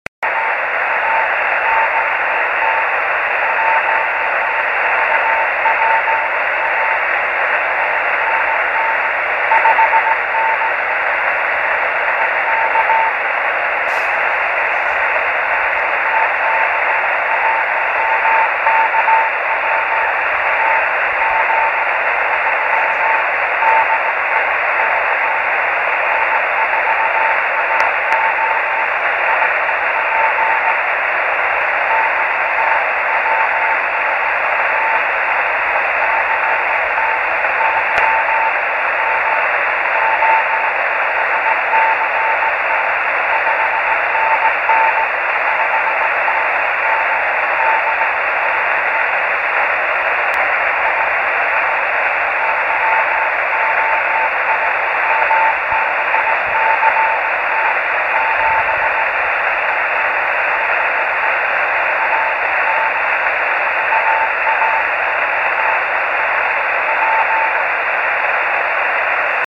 Grabaciones Rebote Lunar de KP4AO,
Radiotelescopio del Observatorio de Arecibo en Puerto Rico
Equipo: Yaesu FT-817.
Antena: Yagi 9 elementos para 432 MHz en boom de madera de 1 m de largo.
2-cw.mp3